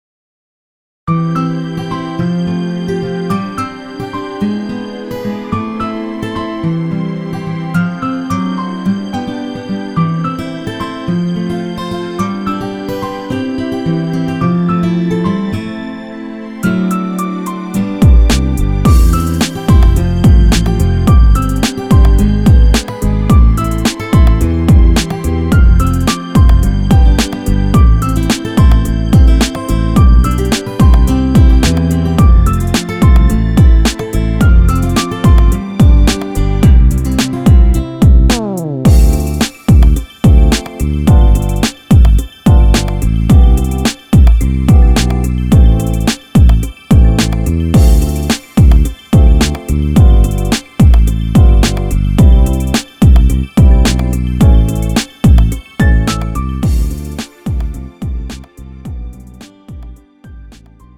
음정 (-1)
장르 가요 구분 Lite MR